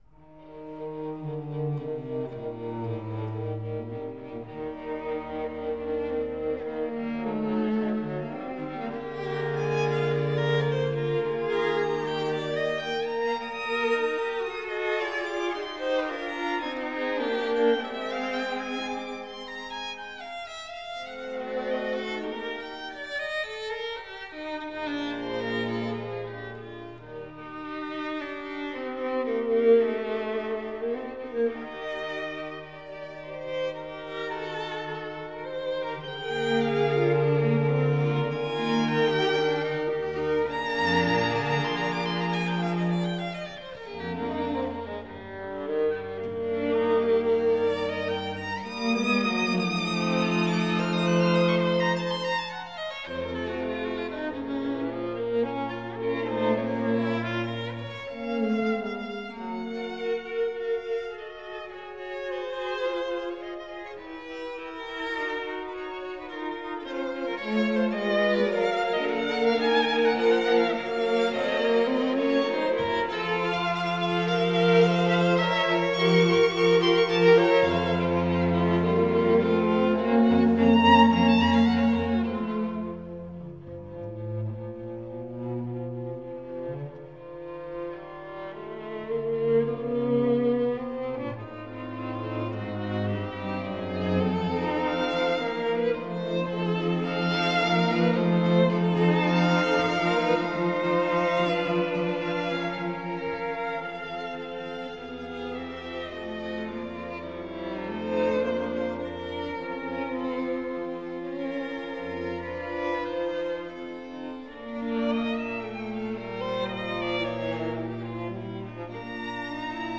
For 2 Violins, Viola & 2 Cellos or 2 Violins 2 Violas & Cello
The opening movement, Allegro, is genial and gentler than Onslow's first movements generally are.